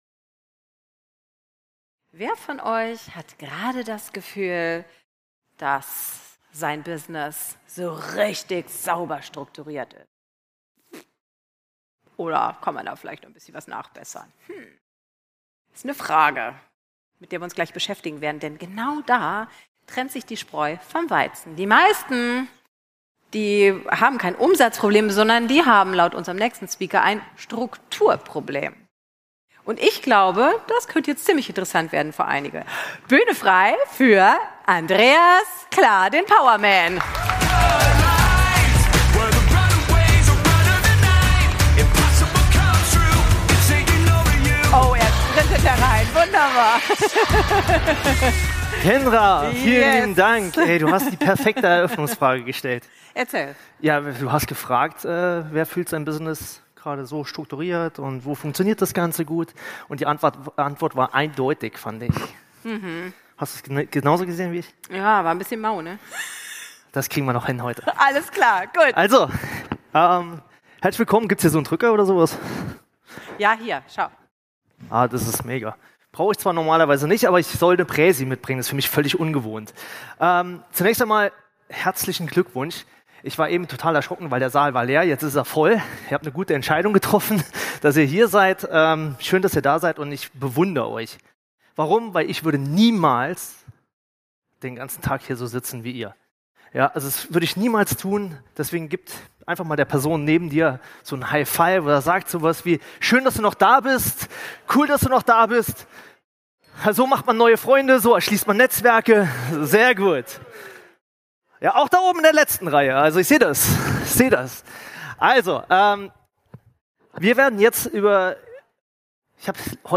Diese Folge ist anders! Und die Gäste auf dem Internet Marketing Kongress haben diese Offenheit gefeiert.